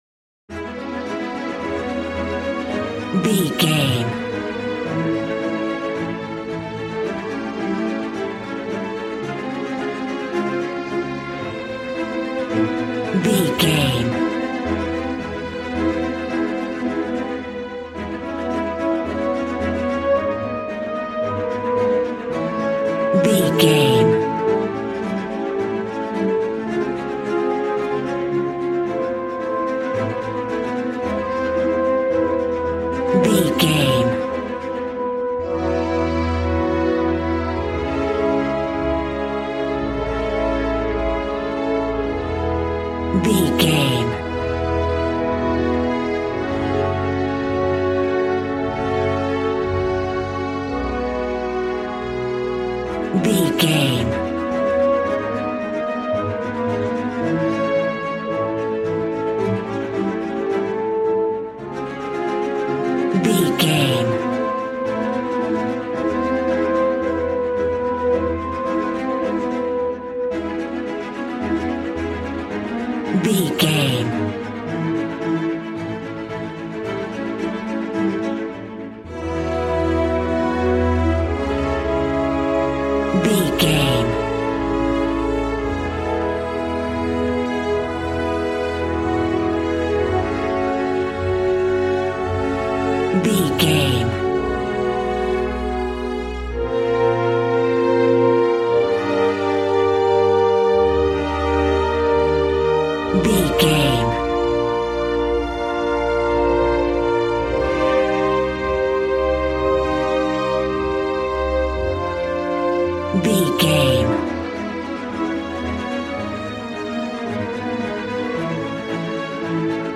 A classical music mood from the orchestra.
Regal and romantic, a classy piece of classical music.
Ionian/Major
regal
cello
violin
strings